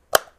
switch23.ogg